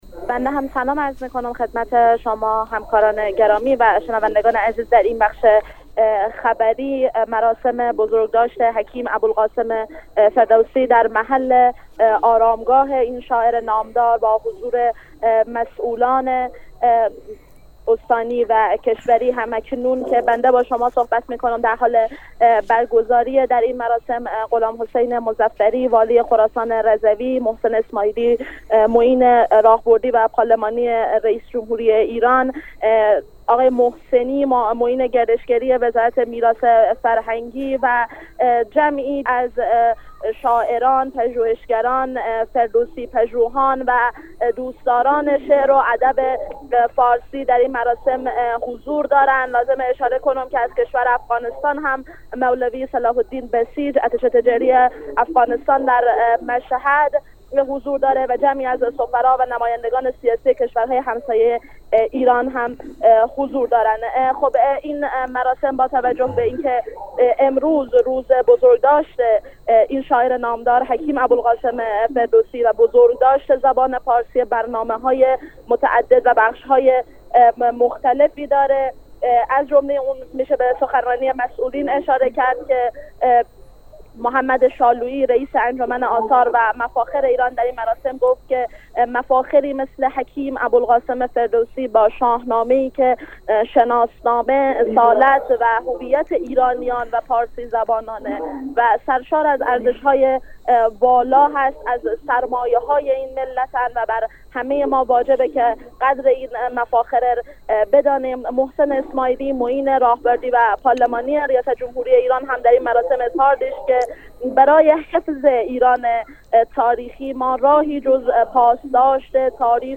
مراسم گرامیداشت روز فردوسی در توس